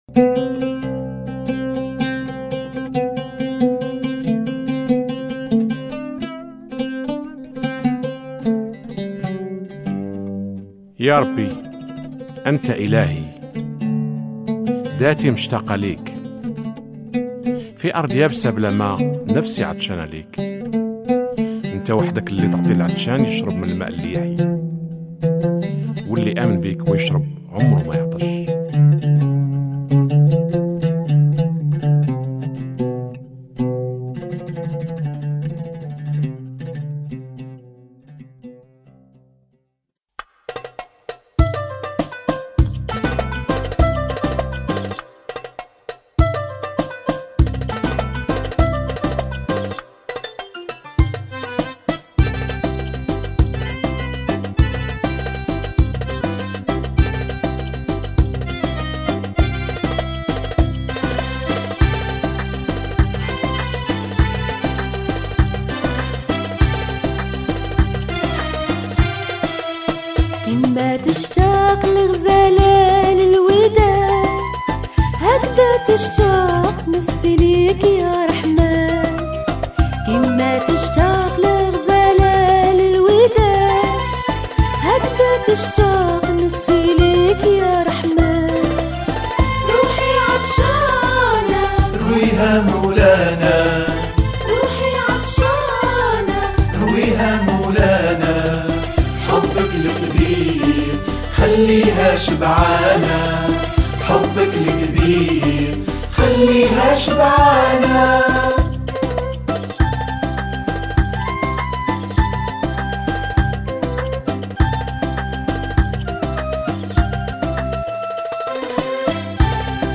ترانيم الدرس 06